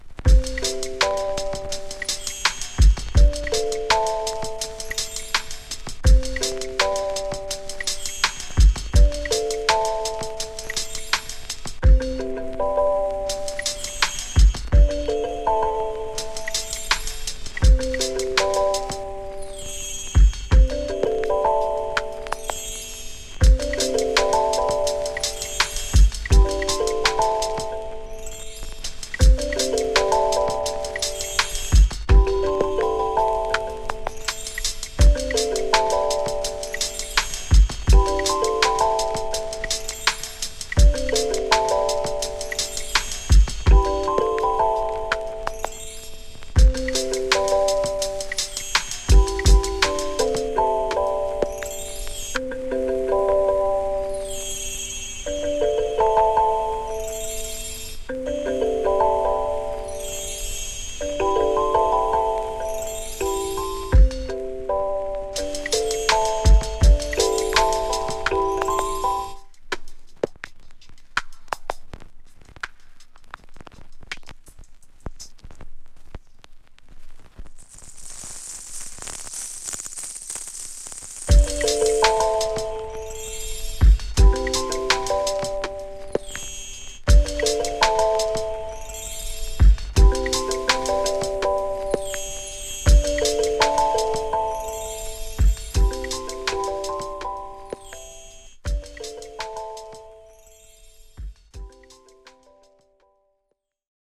> JAZZY BREAK/ELECTRONICA/ABSTRACT